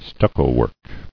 [stuc·co·work]